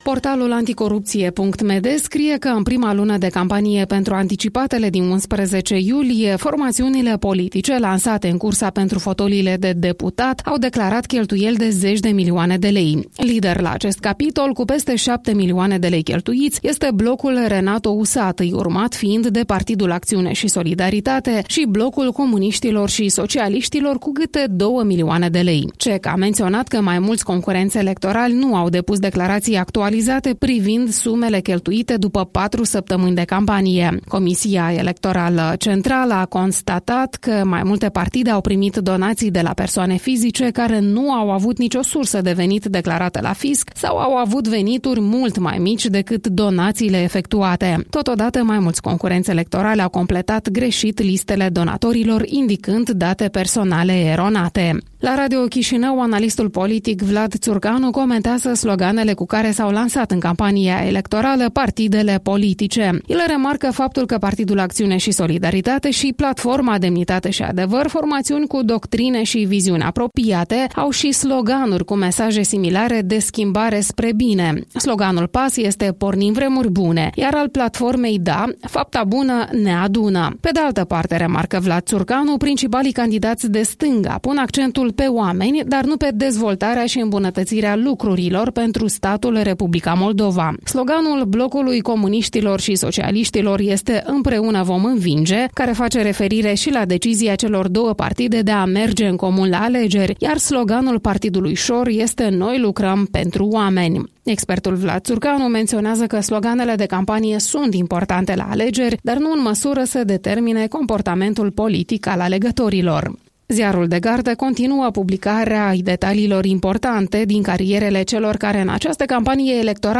Revista presei matinale la radio Europa Liberă